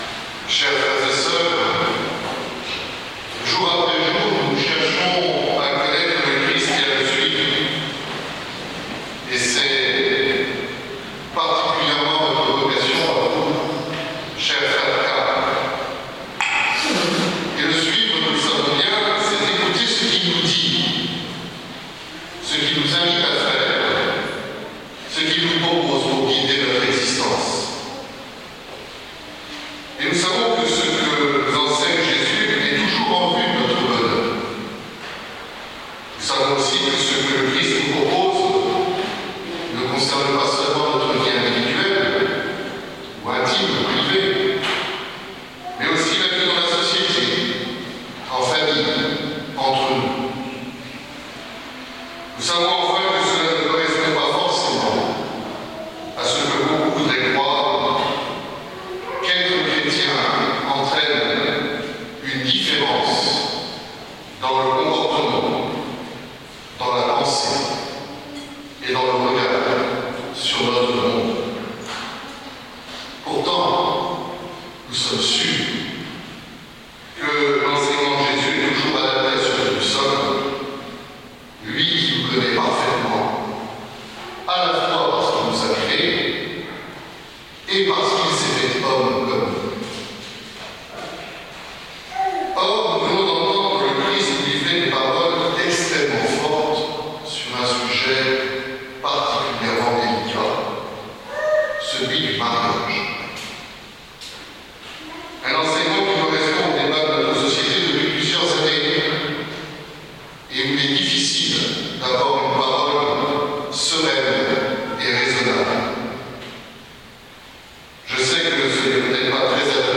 Homélie du 27ème dimanche du Temps Ordinaire par Monseigneur Jean-Marie Le Vert | Les Amis du Broussey
Homelie_vendanges_spirituelles_2018_Mgr_Le_Vert.mp3